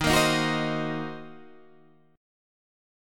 D#m6 chord